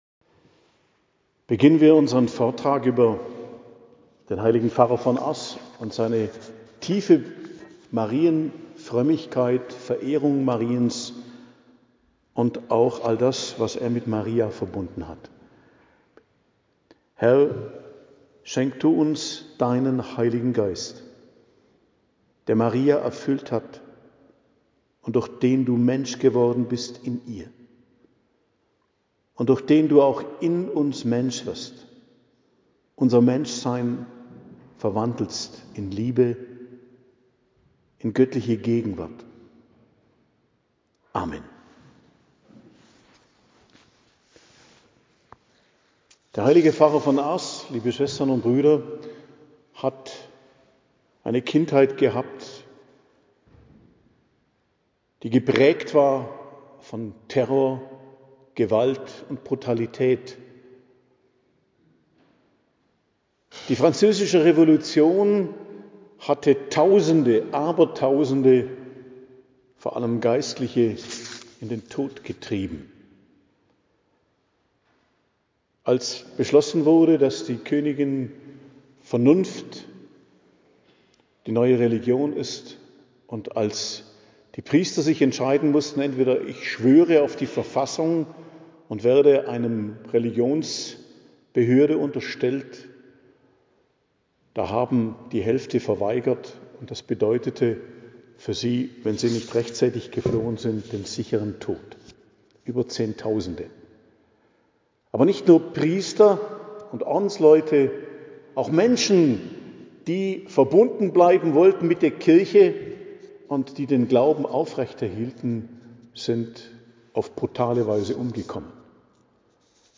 Vortrag
in St.Martin, Oberstadion